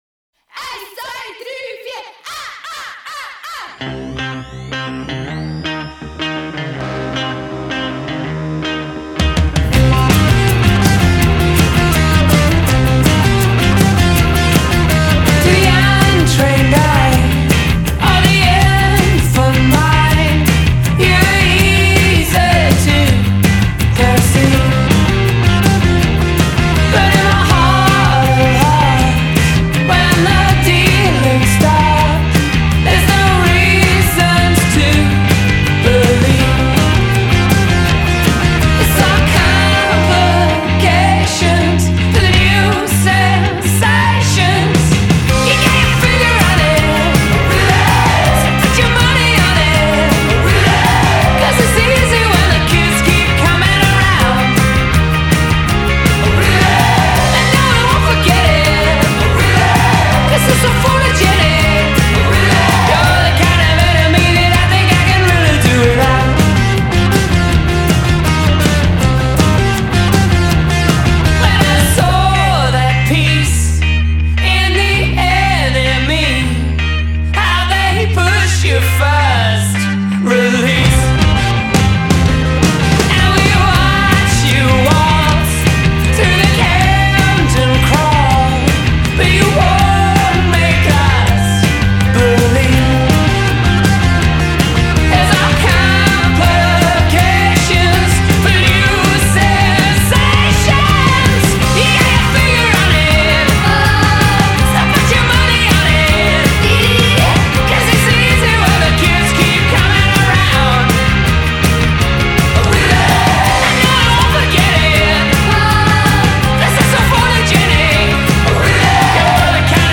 With slinky-swingy energy of elusive provenance
gang-style vocal response in the chorus
a five-piece band based in London.